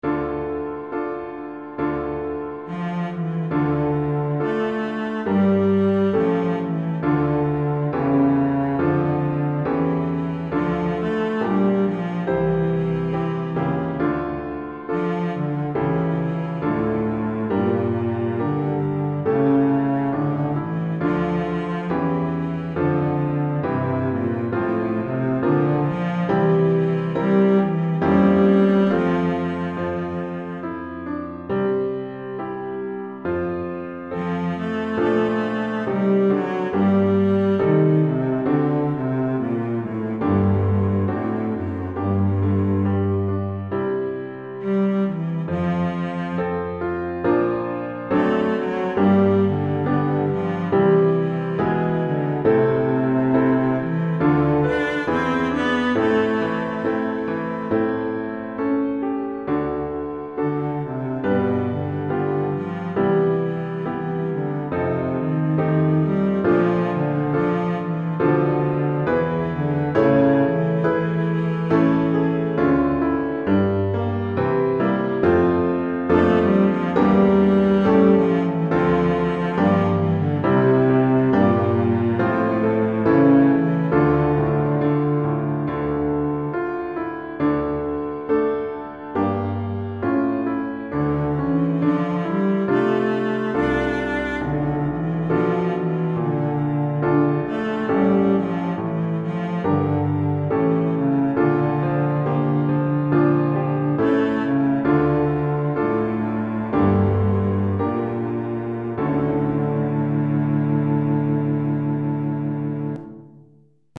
Violoncelle et Piano